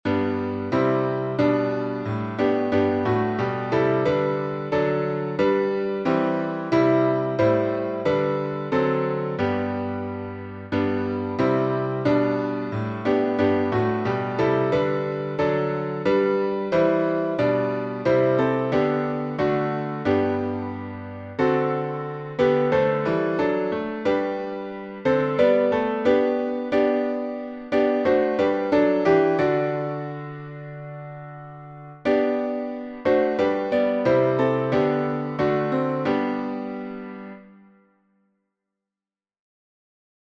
Words by Harry Emerson Fosdick Tune: CWM RHONDDA by John Hughes, 1907 Key signature: G major (